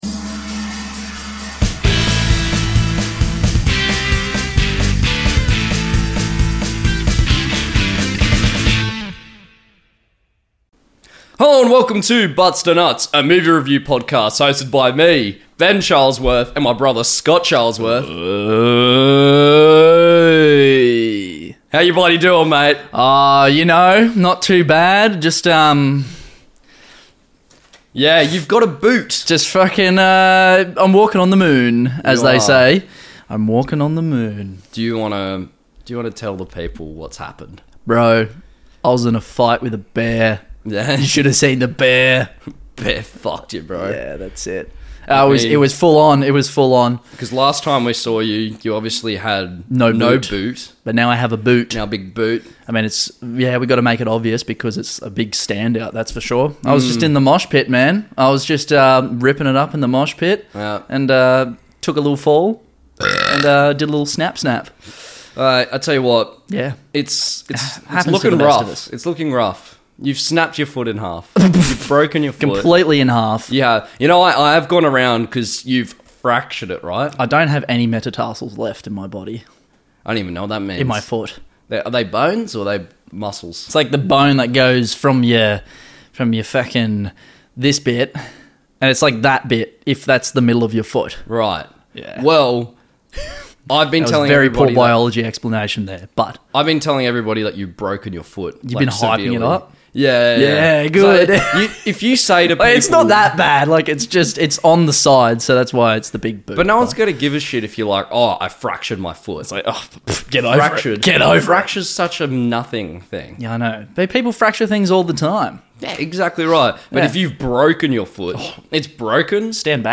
This week the boys reviewed Joker! This episode includes a skinny man, feeling bad for a skinny man and two buff chads doing a script reading.